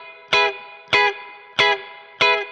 DD_StratChop_95-Emaj.wav